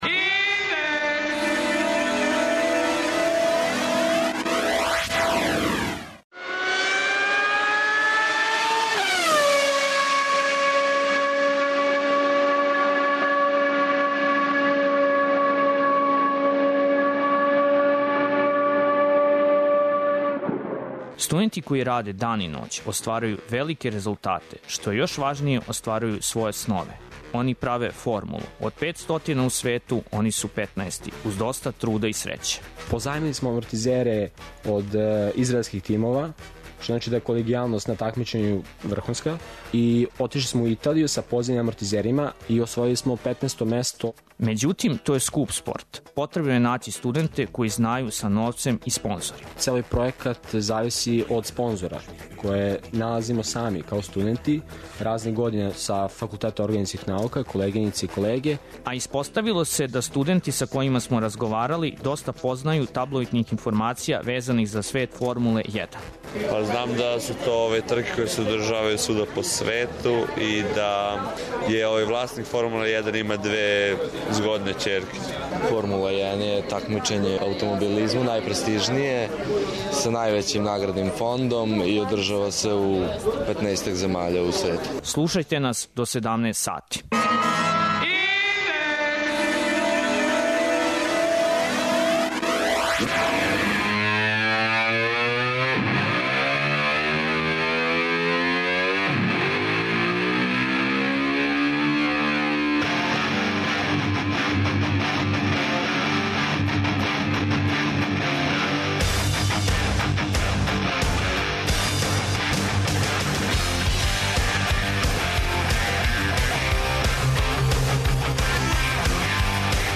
Годинама уназад српски студенти су добри конструктори најбржих возила. Говорићемо о њиховој пасији за таквим послом и о томе како је састав под именом ’’Друмска стрела’’ успео да задиви најбоље на свету својим изумима. Питамо суграђене колико знају о формули и да ли је то само ’’мушки спорт’’.